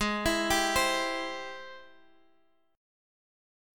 G#+M7 chord